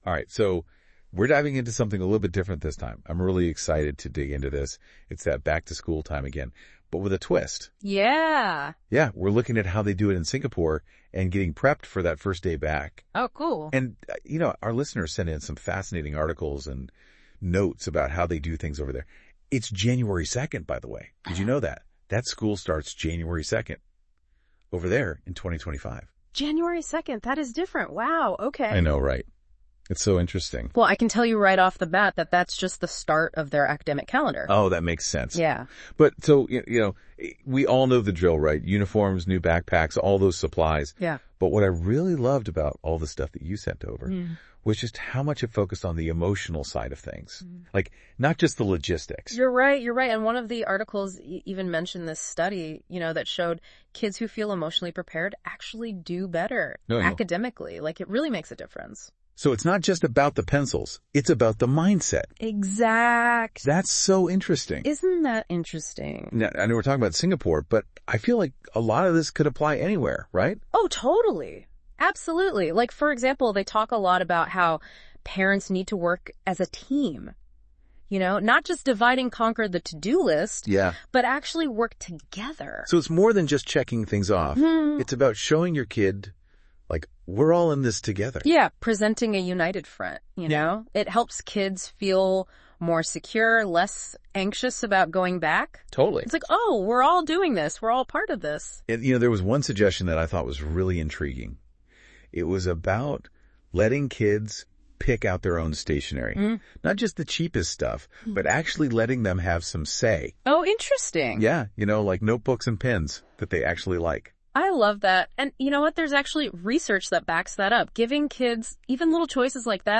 Engaging conversation between two education consultants, exploring essential strategies for a successful first day of school in Singapore . The experts discuss emotional preparation, the importance of family collaboration, and fostering children’s independence through small choices like selecting their own stationery and packing lunches.